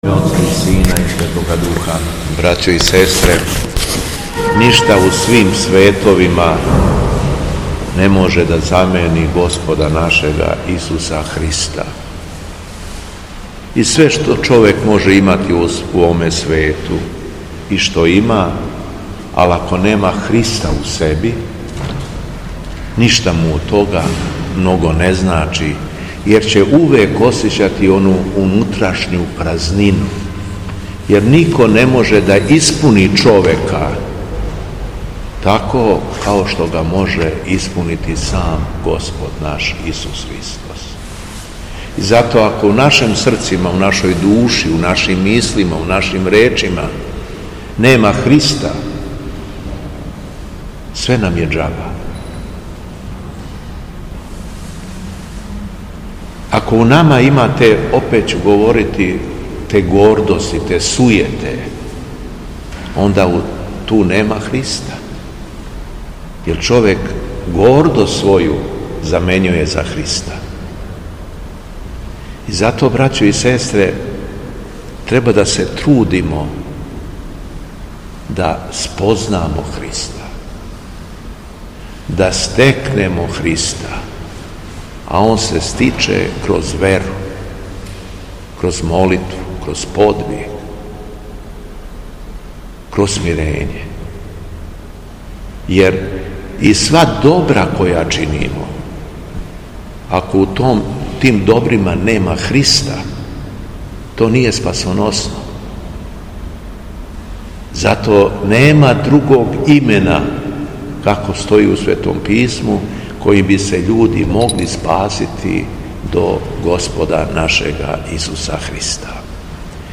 У понедељак, тридесет и четврти по Духовима, када наша света Црква прославља светог мученика Полиевкта, светога Филипа Московског, Његово Преосвештенство Епископ шумадијски Господин Јован служио је свету архијерејску литургију у храму Светога Саве у крагујевачком насељу Аеродром.
Звучни запис беседе - Познати Христа истински и сазнати шта је Он и ко је Он и шта доноси човеку и роду људскоме то је знање које човек са радошћу претвара у свезнање. Јер ако познајеш Христа и живиш са Христом ти си испуњен свезнањем, јер нема ништа веће од Христа.